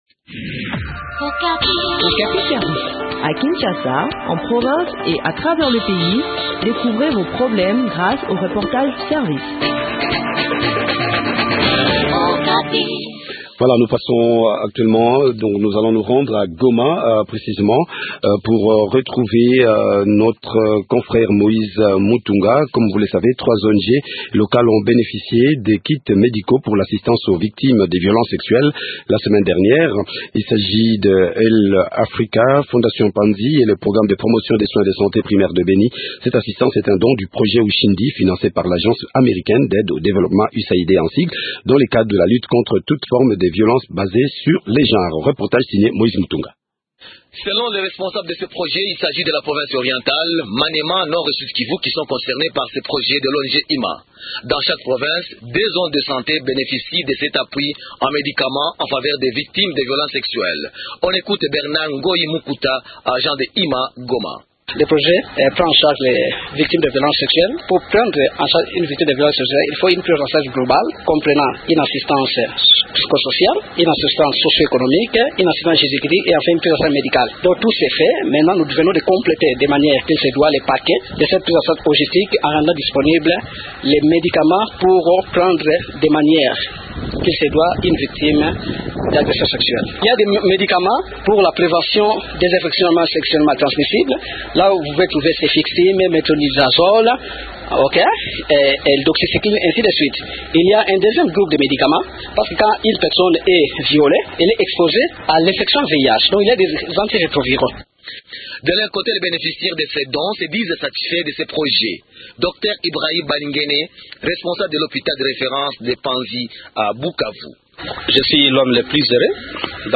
donne des précisions sur l’exécution de ce projet au micro